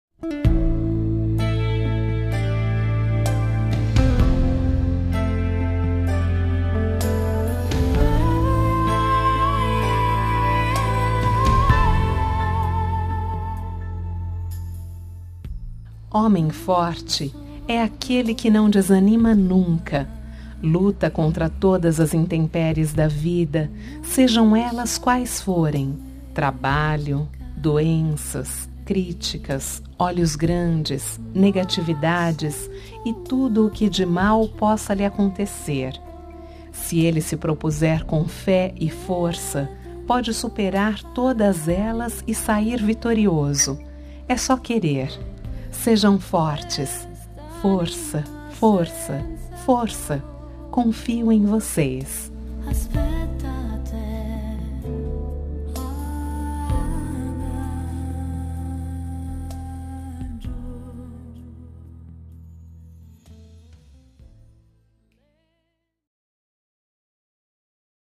Telemensagem de Otimismo – Voz Feminina – Cód: 100108 – Força